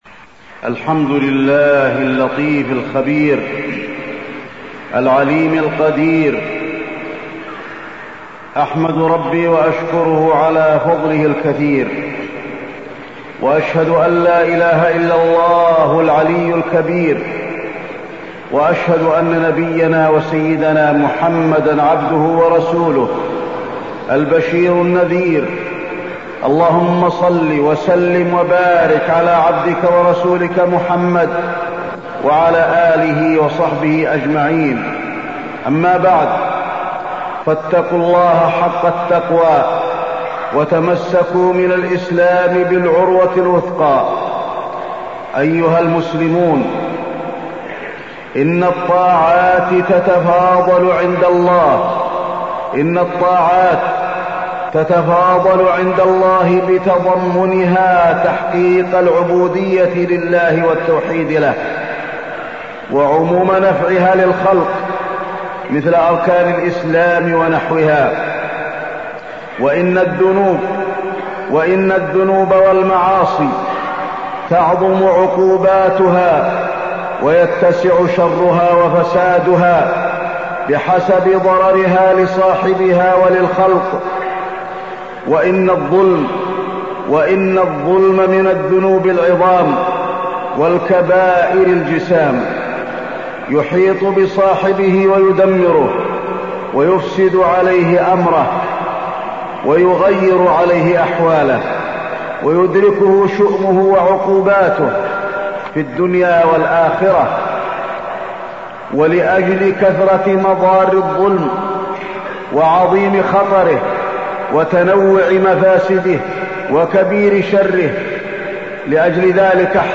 تاريخ النشر ١٧ شعبان ١٤٢٥ هـ المكان: المسجد النبوي الشيخ: فضيلة الشيخ د. علي بن عبدالرحمن الحذيفي فضيلة الشيخ د. علي بن عبدالرحمن الحذيفي الظلم